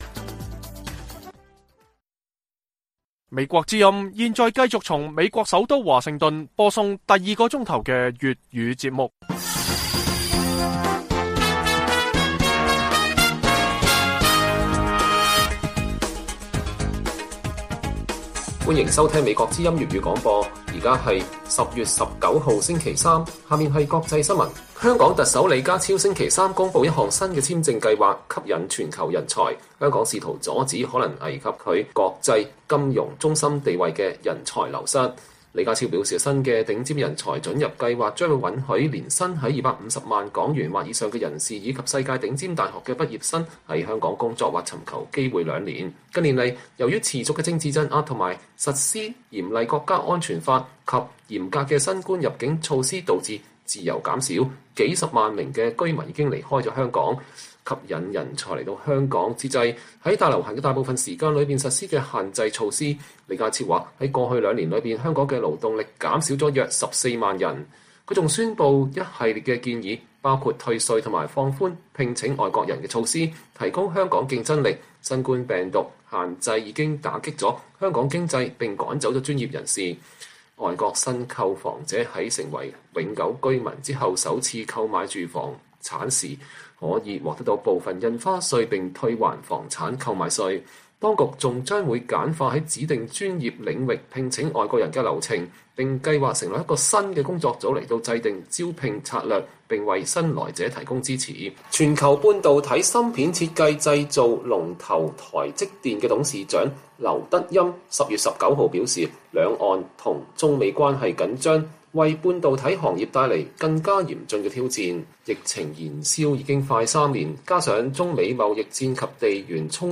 粵語新聞 晚上10-11點: 李家超公佈首份施政報告企圖為香港挽留人才